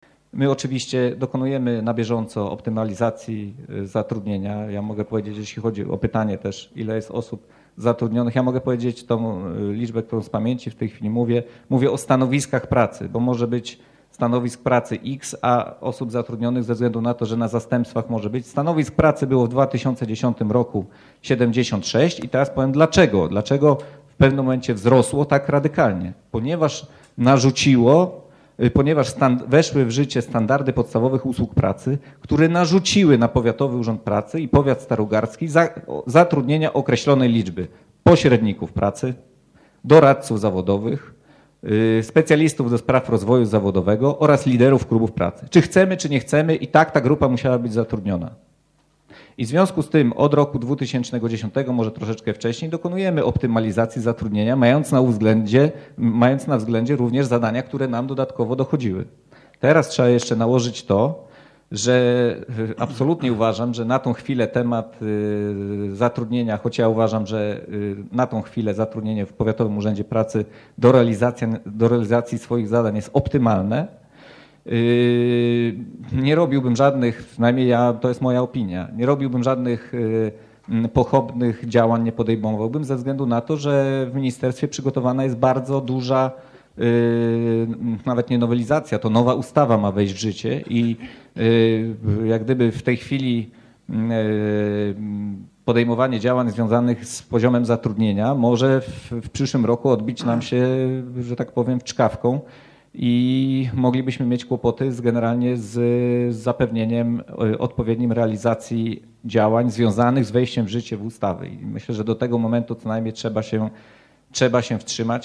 Co więcej podczas ostatniej sesji Rady Powiatu radny Prawa i Sprawiedliwości stwierdził, że w Powiatowym Urzędzie Pracy zauważa wyraźny przerost zatrudnienia.
Dyrektor Powiatowego Urzędu Pracy wyjaśnił, że praca urzędników PUP jest złożona, a zadań jest więcej niż tylko walka z bezrobociem.